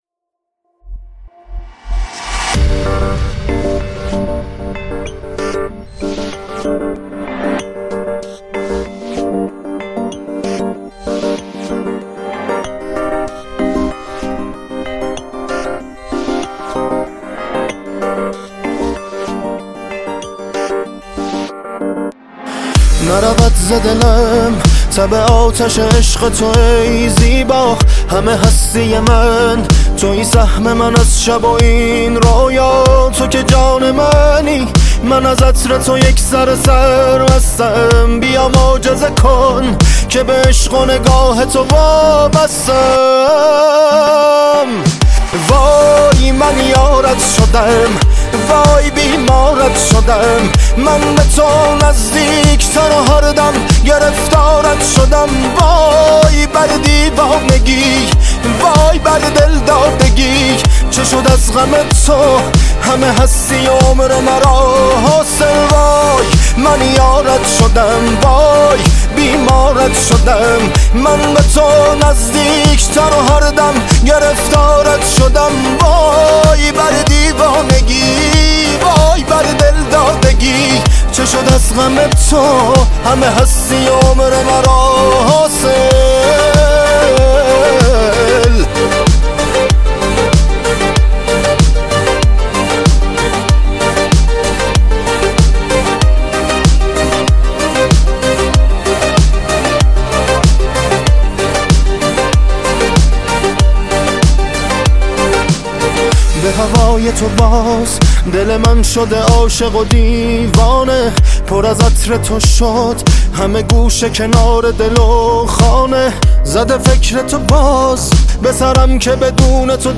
آهنگ شاد ایرانی